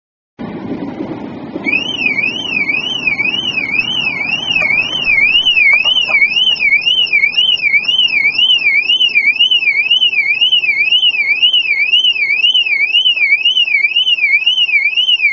Diebstahlwarnanlage
Die serienmäßige Hupe der DWA ist echt eine Zumutung.